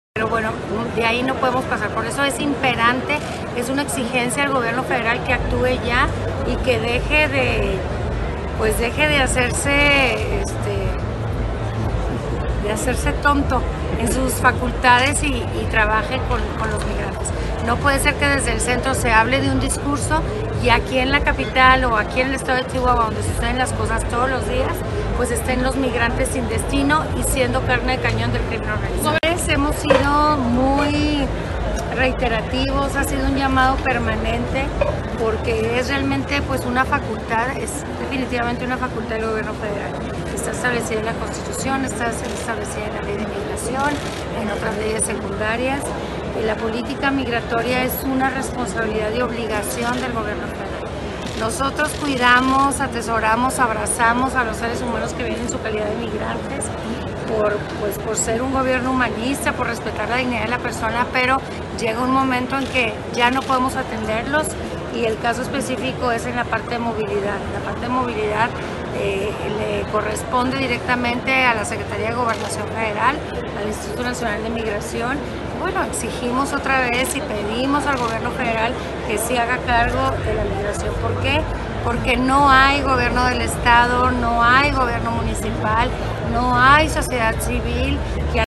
AUDIO: MARÍA EUGENIA CAMPOS, GOBERNDORA DEL ETADO DE CHIHUAHUA
MARU-CAMPOS-MIGRACION-.mp3